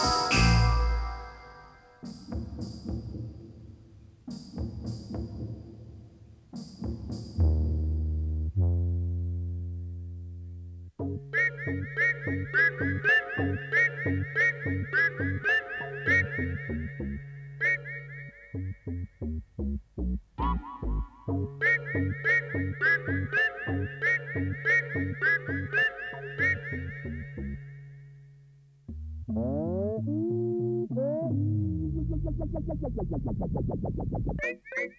New Age Electronic Classical Modern Era
Жанр: Электроника / Классика